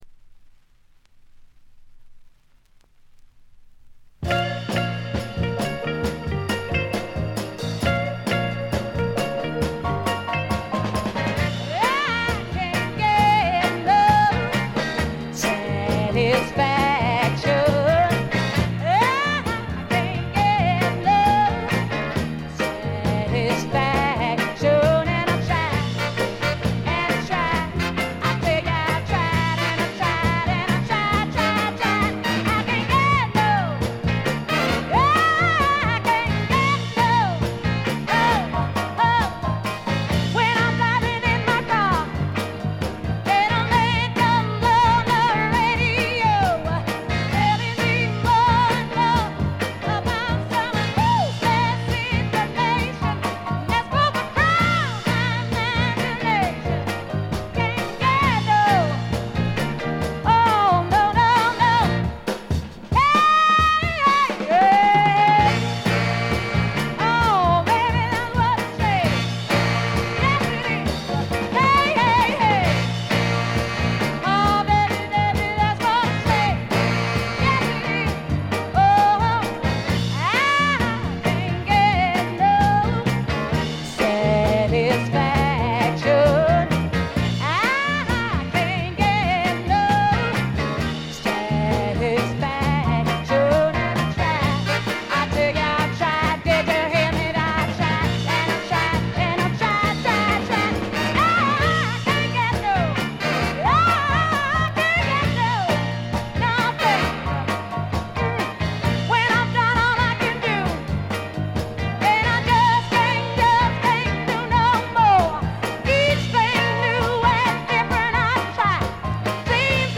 ほとんどノイズ感無し。
試聴曲は現品からの取り込み音源です。
vocals, piano